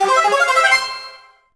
slug_good_kill_01.wav